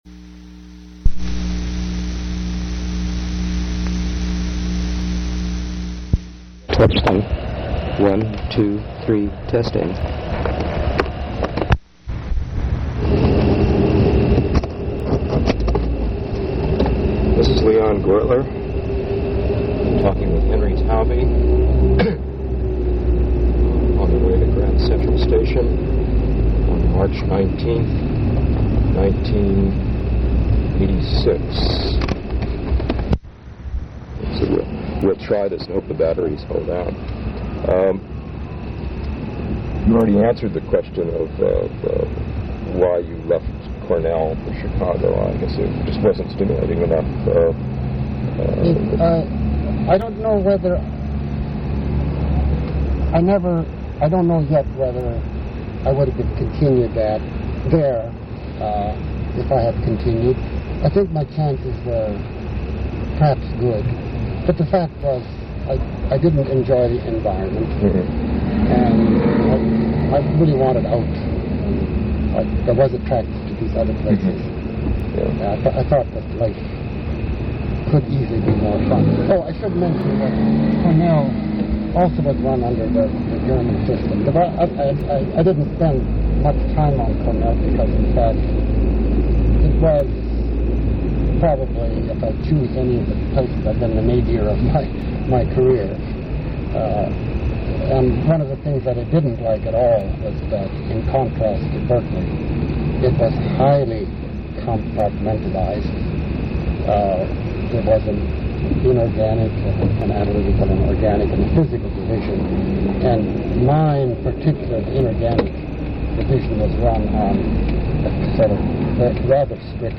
Place of interview Grand Central Terminal (New York, N.Y.)
Genre Oral histories